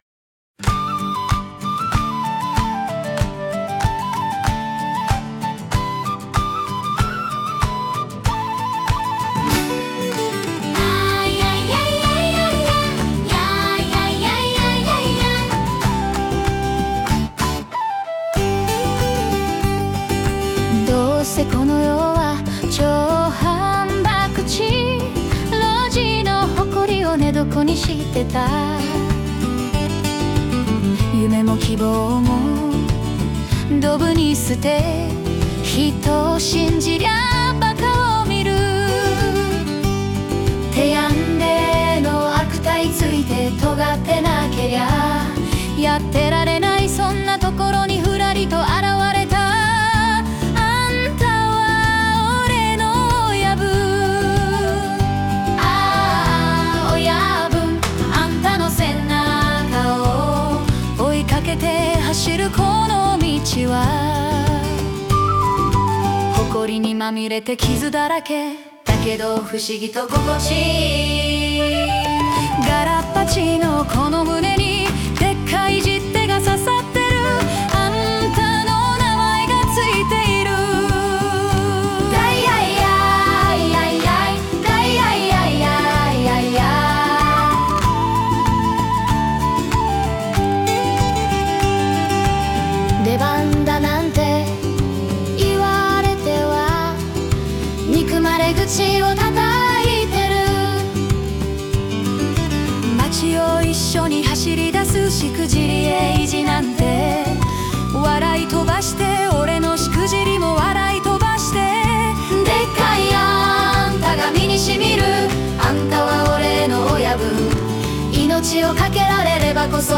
🎵 主題歌